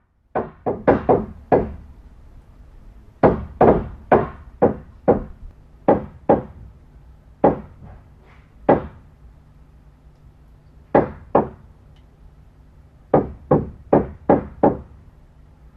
《トンカチ》フリー効果音
トントントントン、トンカチの効果音。
tonkachi.mp3